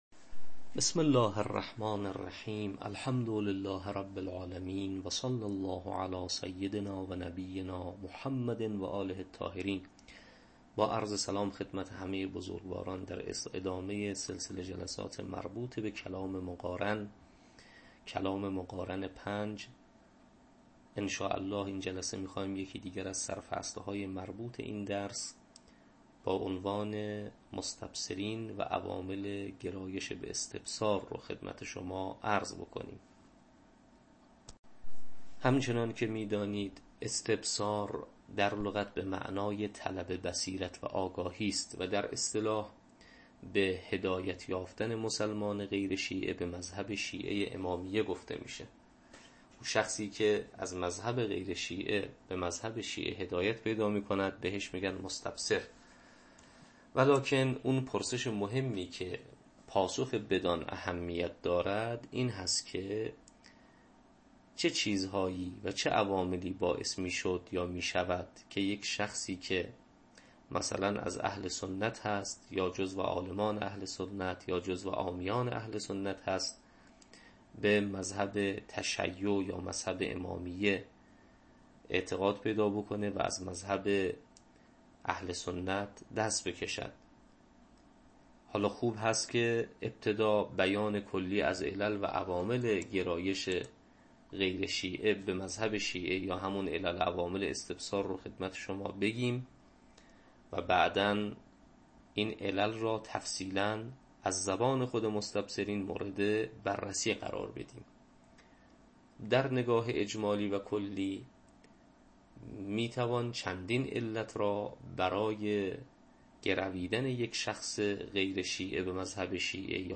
تدریس کلام مقارن